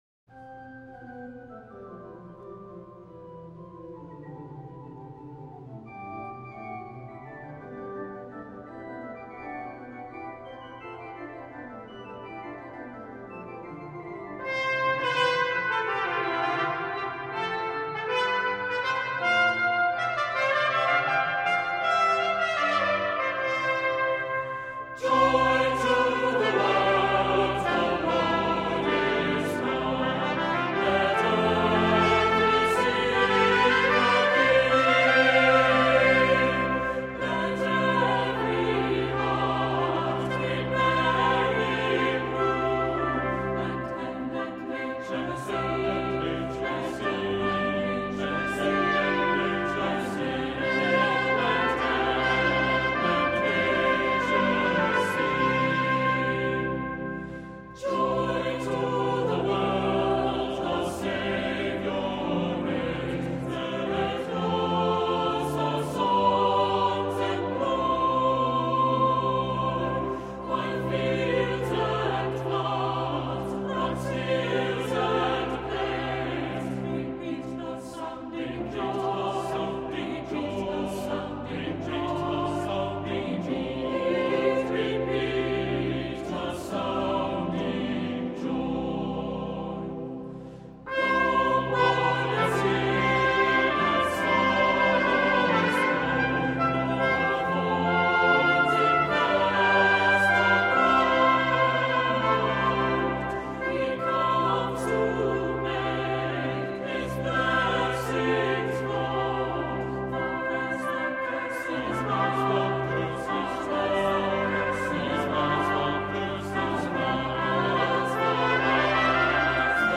Voicing: SAB; Assembly